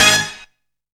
LO BRASSHIT.wav